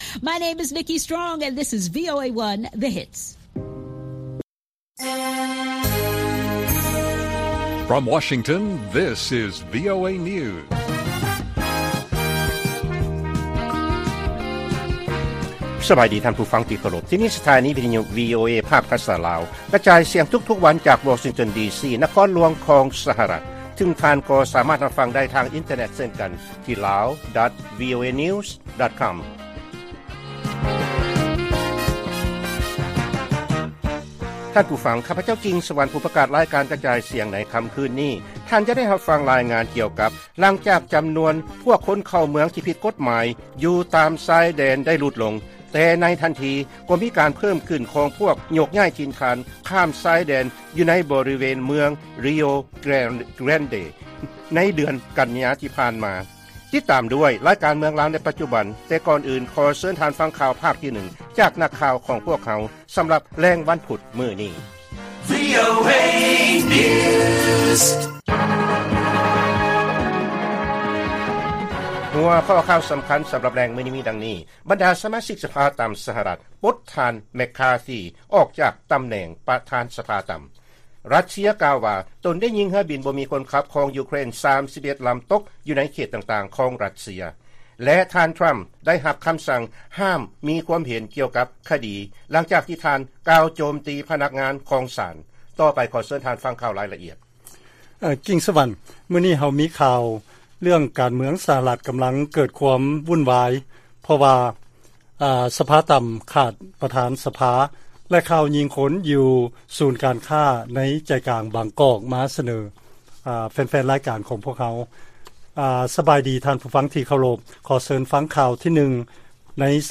ລາຍການກະຈາຍສຽງຂອງວີໂອເອ ລາວ: ບັນດາສະມາຊິກສະພາຕ່ຳ ສະຫະລັດ ປົດທ່ານເມັກຄາຣທີ ອອກຈາກຕຳແໜ່ງ ປະທານສະພາຕ່ຳ